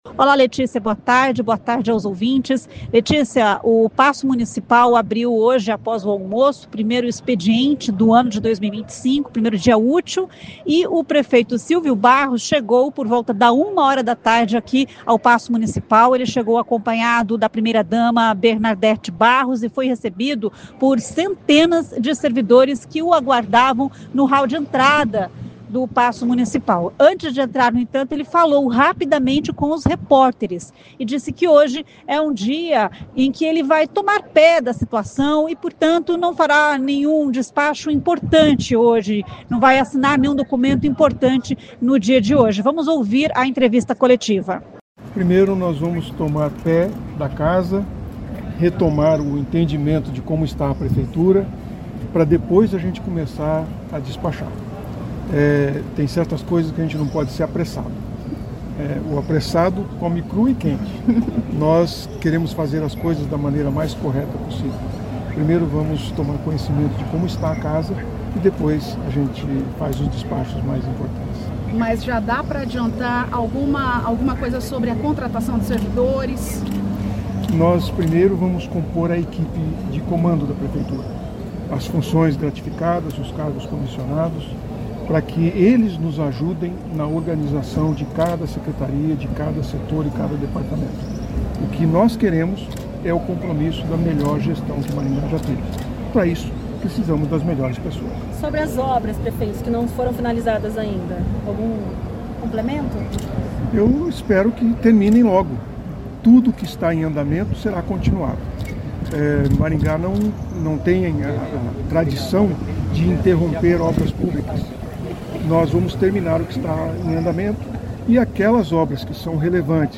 Foi o que disse o prefeito Silvio Barros em coletiva de imprensa no primeiro dia de trabalho da nova gestão.